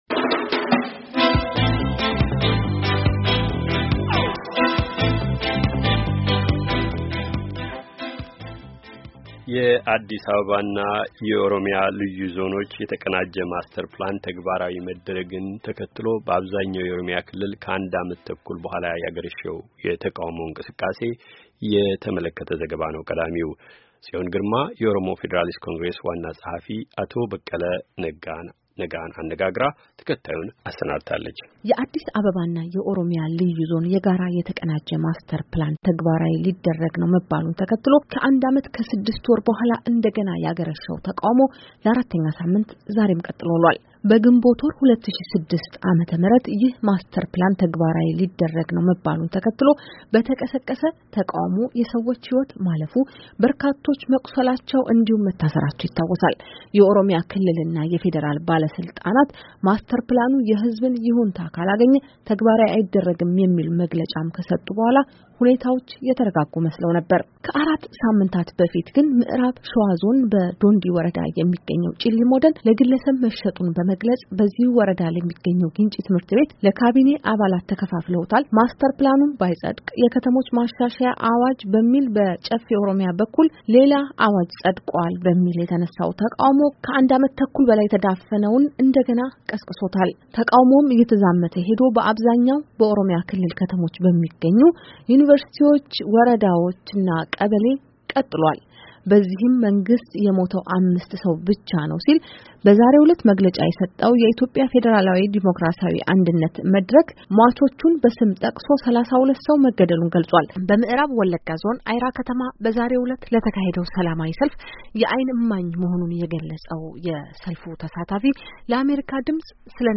የተካሄደ ቃለ ምልልስና በርዕሱ ዙሪያ የተጠናከረ ዘገባ።